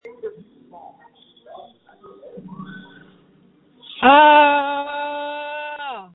JUST SCREAM! Screams from December 5, 2020
• When you call, we record you making sounds. Hopefully screaming.
You might be unhappy, terrified, frustrated, or elated.